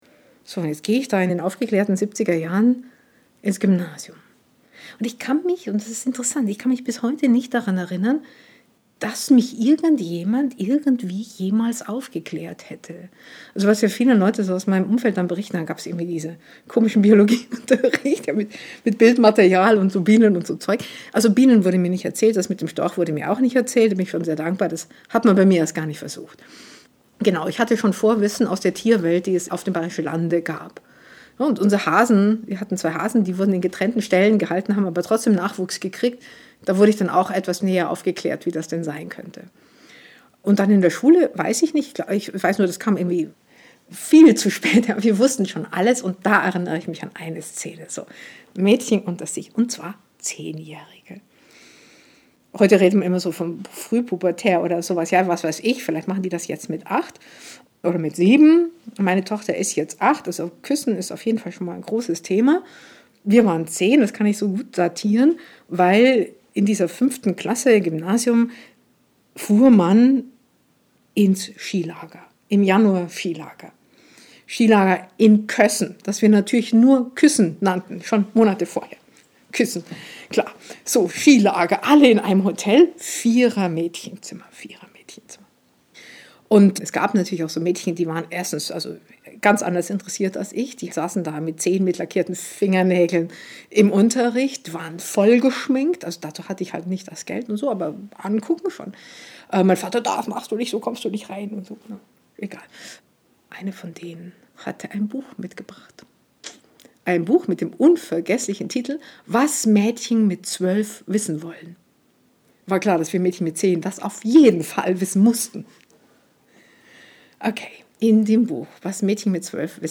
Erzählerin: Ulrike Draesner